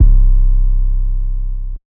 {808} MurdaBass Mod.wav